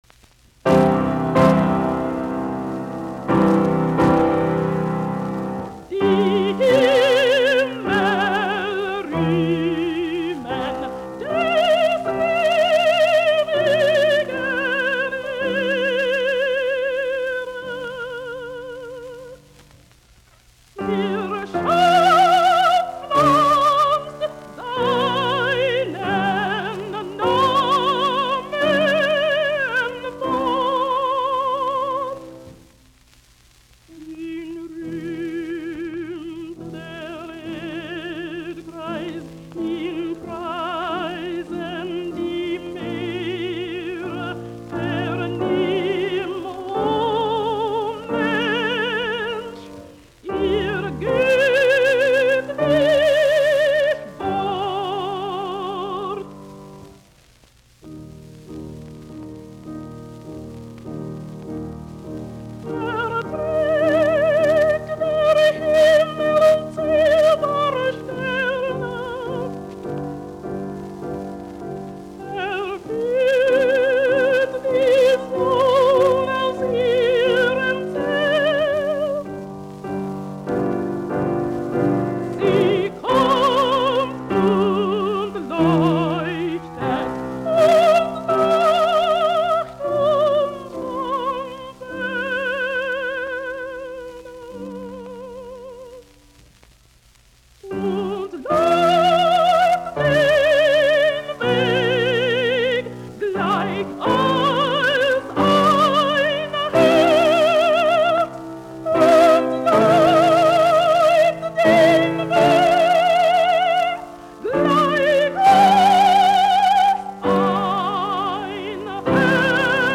Laulut, lauluääni, piano, op48